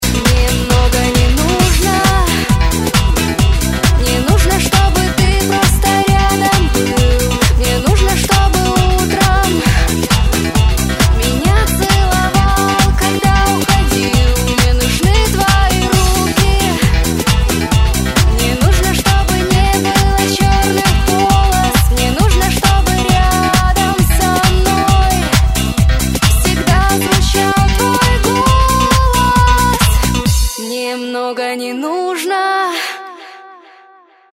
• Качество: 128, Stereo
попса